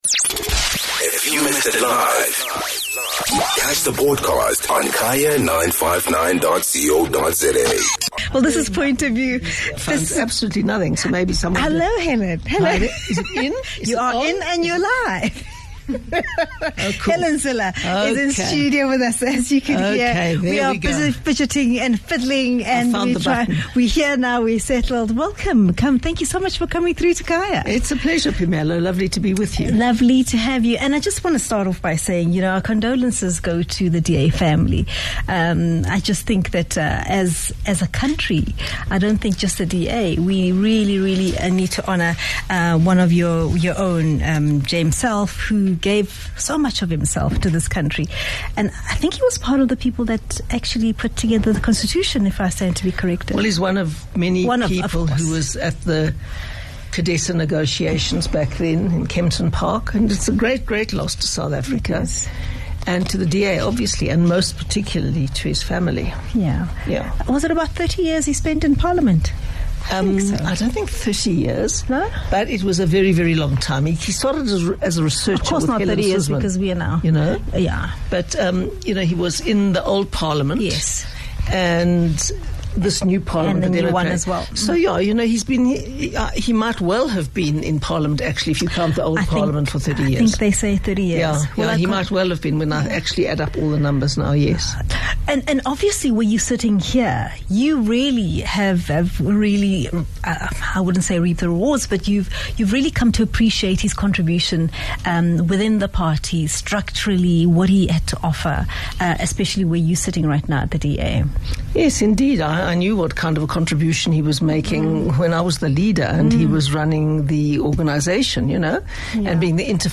With only a few days left before the national elections, DA's federal council Helen Zille joinsPoint of View instudio where she shares her views on the possible election 2024 outcome and what the DA.